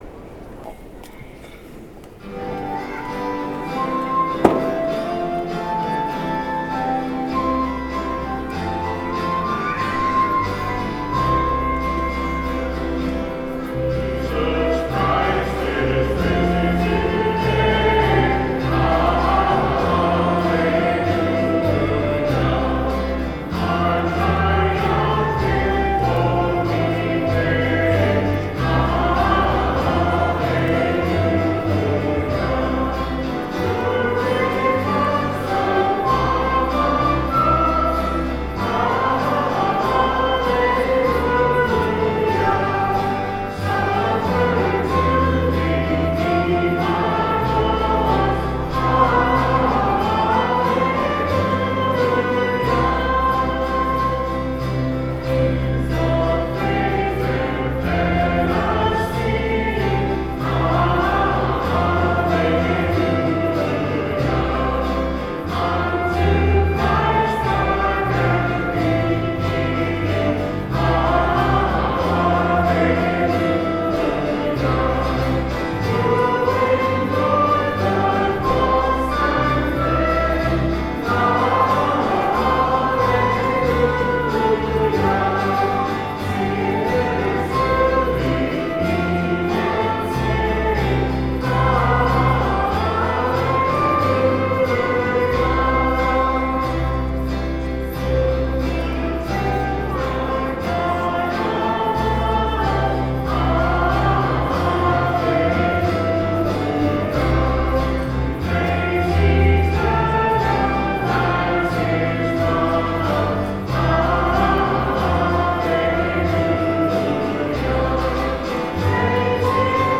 Music from the 10:30 Mass Easter Sunday, March 31, 2013:
Now the guitar is coming out a little better.)